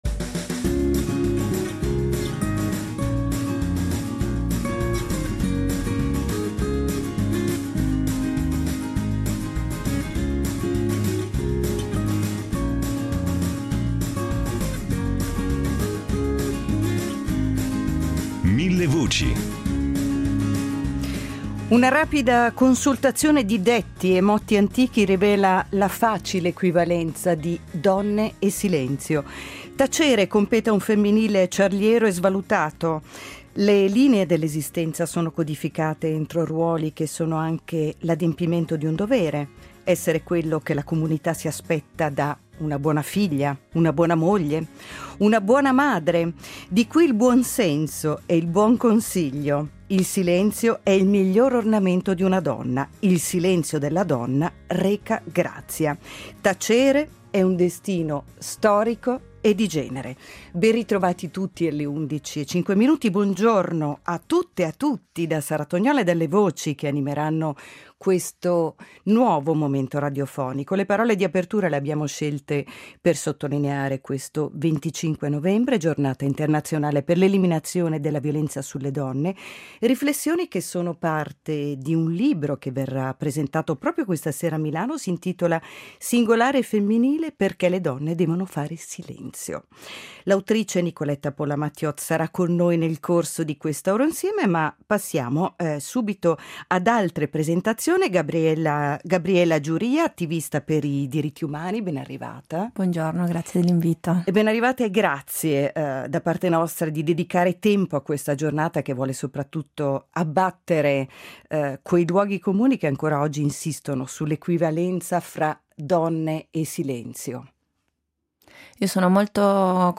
attivista per i diritti umani
insegnante di danza e mediatrice culturale